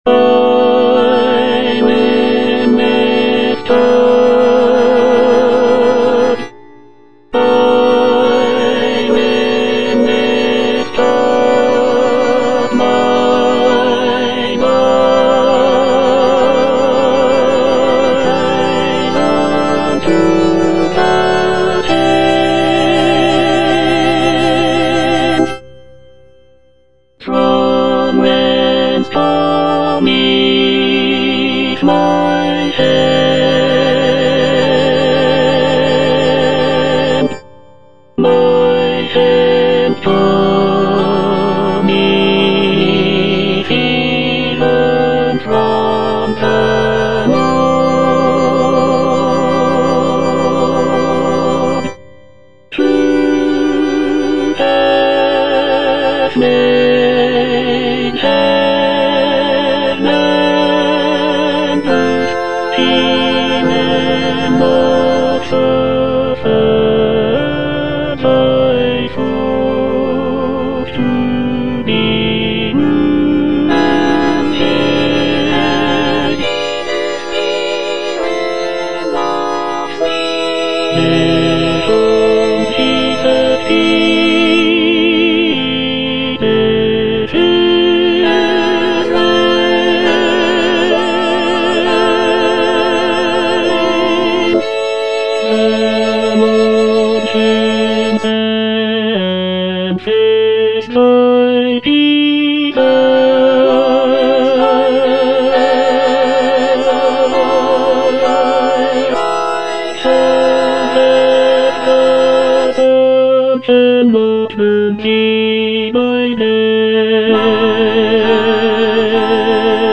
Tenor I (Emphasised voice and other voices)
choral work